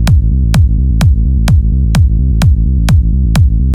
• techno bass and kick tremolo.wav
techno_bass_and_kick_tremolo_eFU.wav